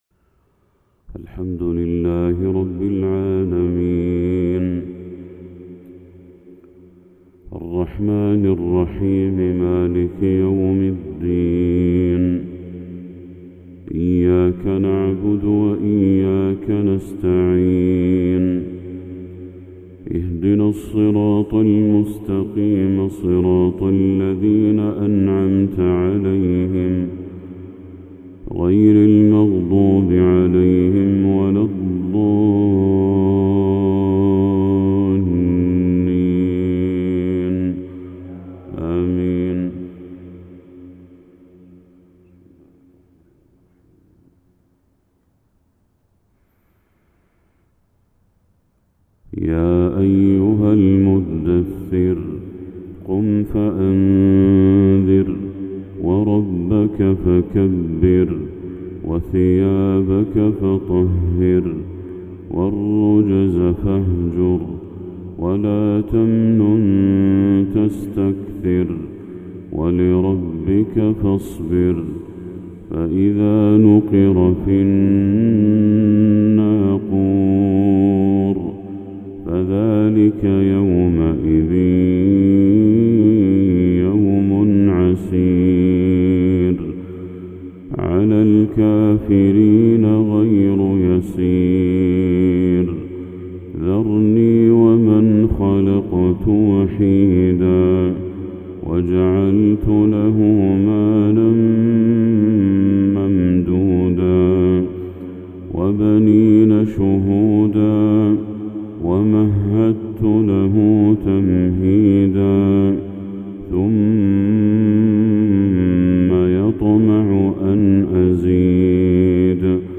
تلاوة للشيخ بدر التركي سورة المدثر كاملة | فجر 17 ذو الحجة 1445هـ > 1445هـ > تلاوات الشيخ بدر التركي > المزيد - تلاوات الحرمين